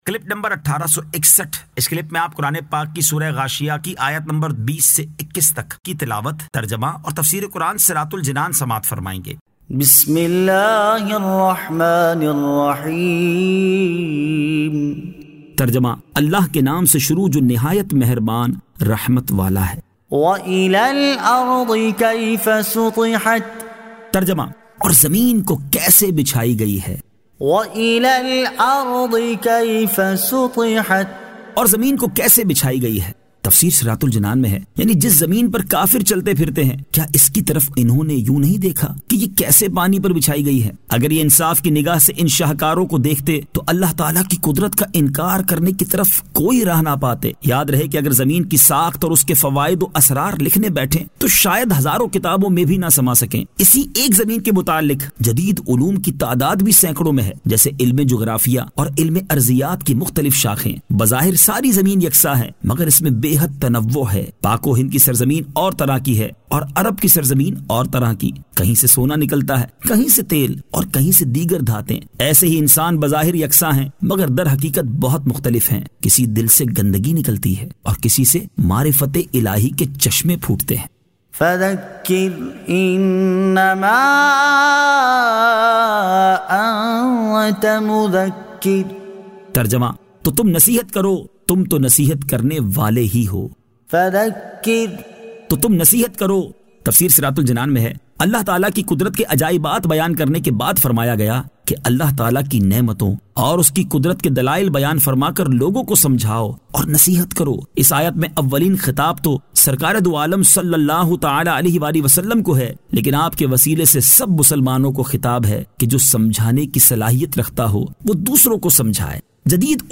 Surah Al-Ghashiyah 20 To 21 Tilawat , Tarjama , Tafseer